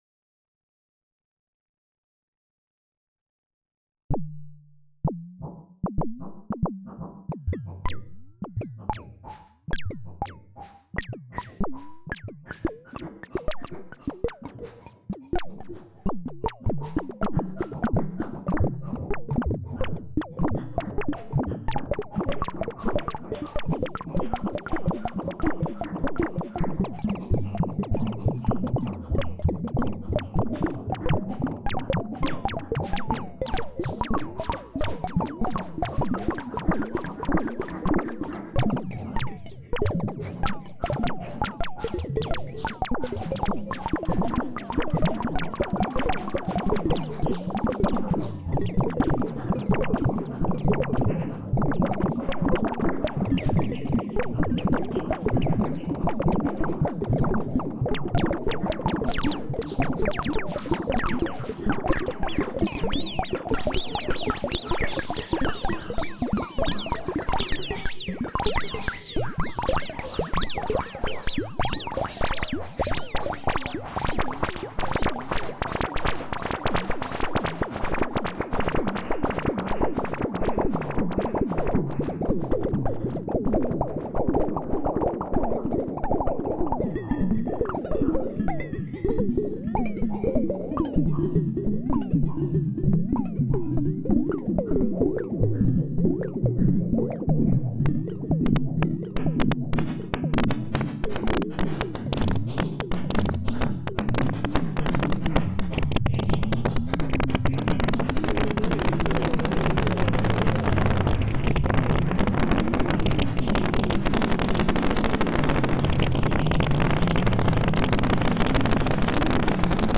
One bubbly track of UT Impulse. Slewed S&H LFOs on filter frequency and FX’s delay time. I add more and more Euclidean trigs, mess with filter envelope times and speed up the clock.
Reverb predelay is maxed out and decay time at minimum.